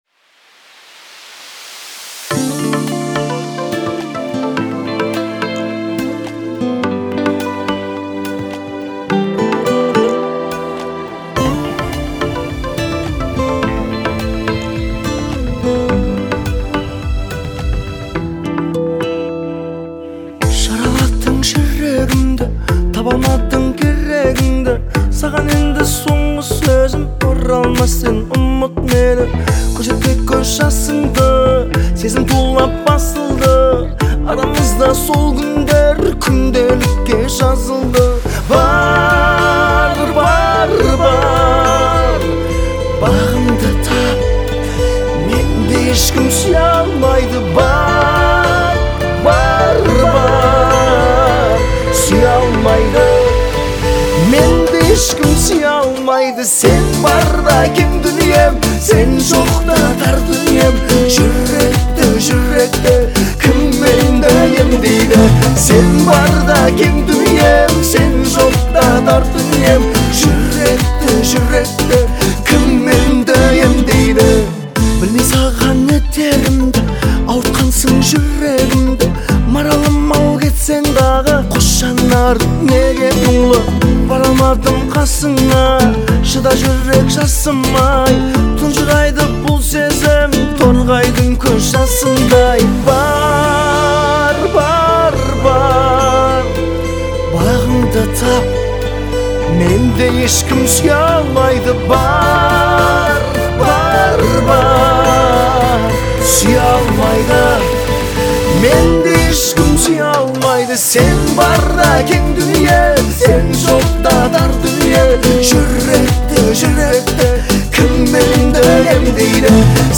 харизматичным исполнением и мощным голосом